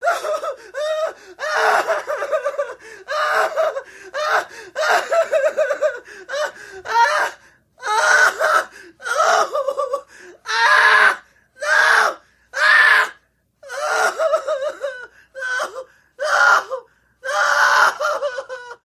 Звуки мужского плача
Звук: Отчаянный рыдания юноши